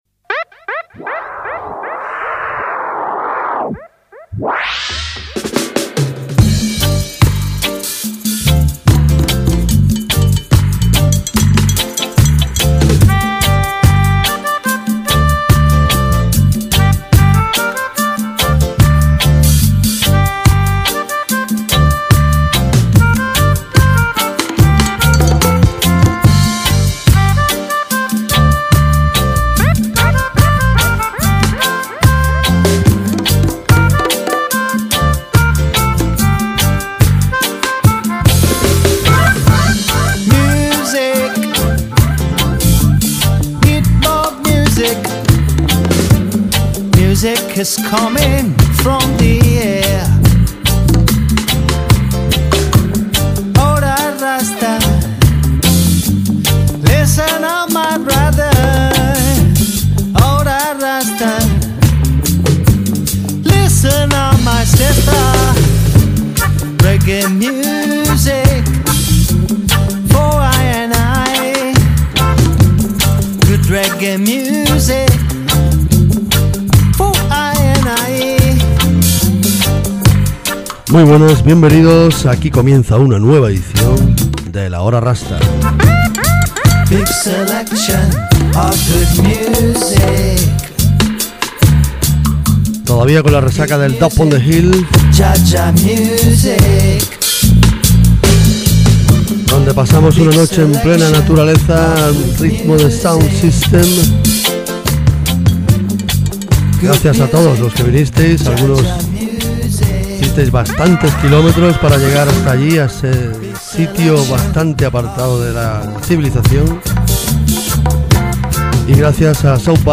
crucial vinyl selection